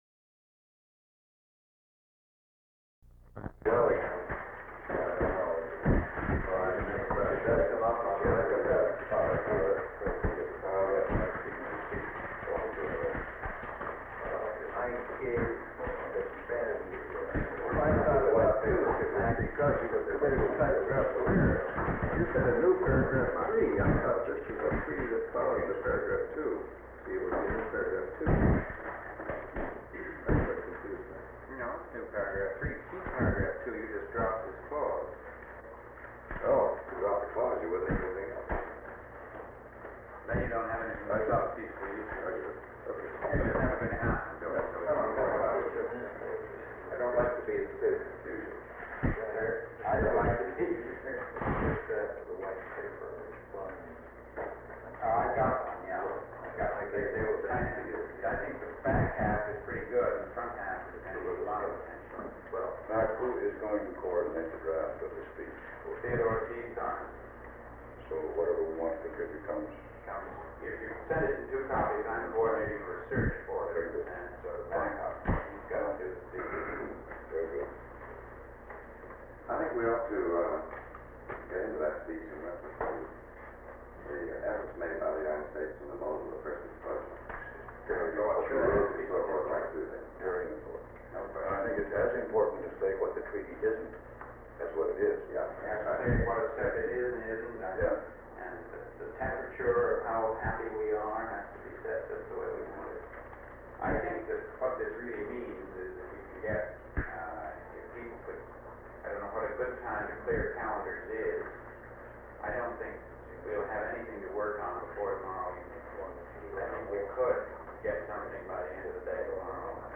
Sound recording of a meeting that seems to be held on July 24, 1963, between President John F. Kennedy, Secretary of State Dean Rusk, Under Secretary of State George Ball, Ambassador at Large Llewellyn Thompson, Director of United States Arms Control and Disarmament Agency (ACDA) William Foster, Deputy Under Secretary of State for Political Affairs U. Alexis Johnson, Director of the United States Information Agency (USIA) Edward R. Murrow, Benjamin Read, Special Assistant to the President for National Security Affairs McGeorge Bundy, and Executive Secretary of the National Security Council (NSC) Bromley Smith. This meeting is mostly a continuation on of the previous day’s staff discussion on the language of instructions to Under Secretary of State W. Averell Harriman, the language of the treaty banning atmospheric nuclear weapons tests, later known as the Partial Test Ban Treaty (PTBT) or the Limited Test Ban Treaty (LTBT), and the note on the treaty, as well as the wording of a speech by President Kennedy. Shortly after the meeting begins President Kennedy leaves to call former President Harry S. Truman to discuss the Test Ban Treaty and does not return for the remainder of the meeting.